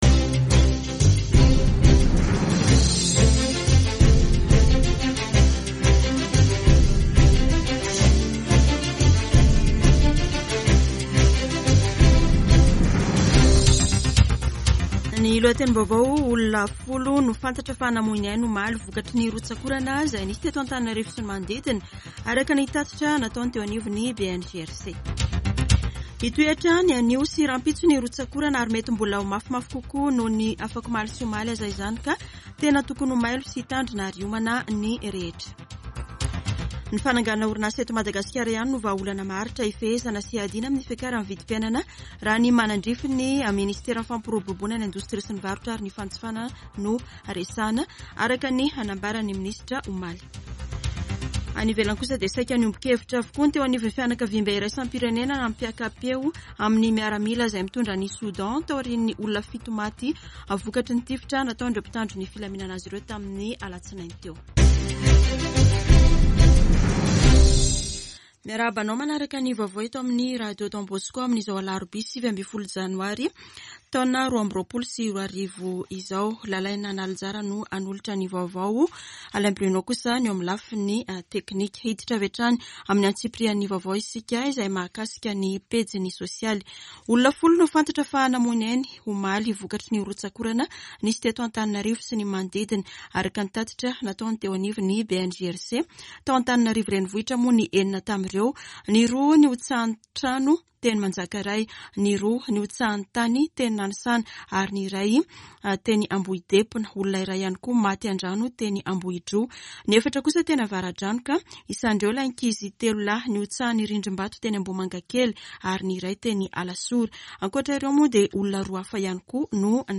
[Vaovao maraina] Alarobia 19 janoary 2022